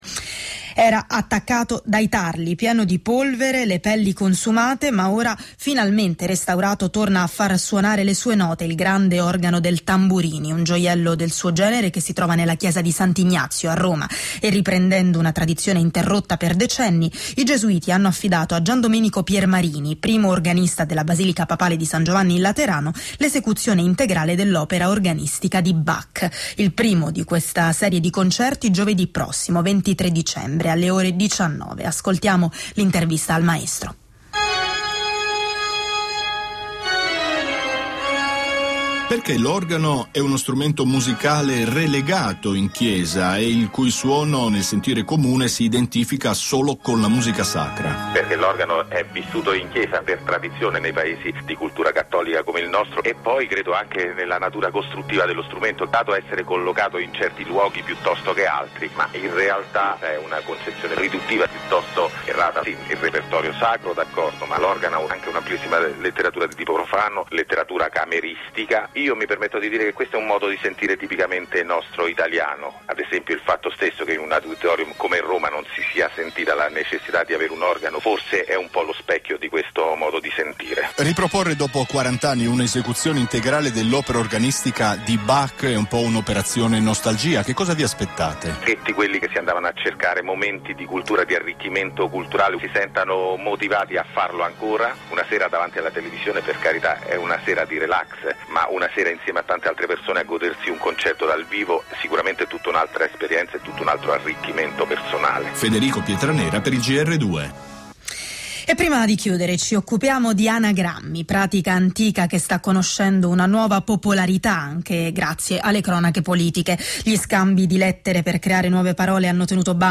Interviste radiofoniche